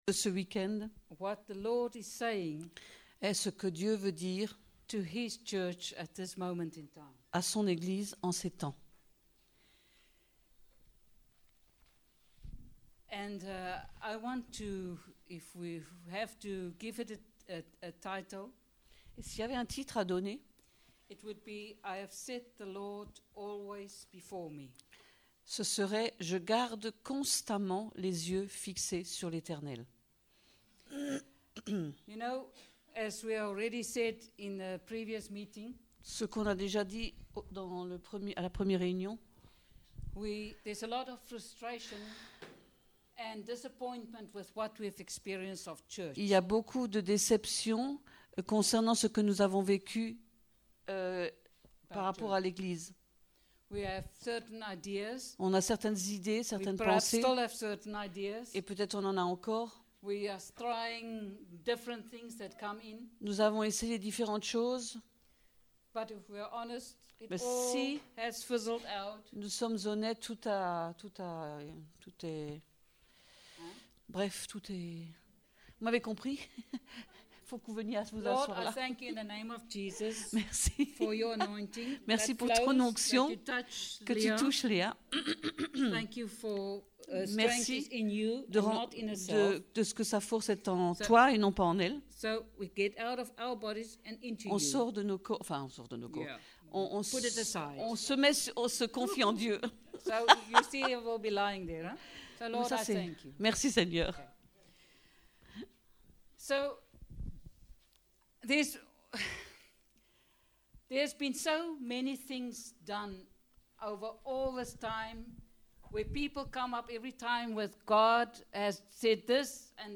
Type De Service: Conférence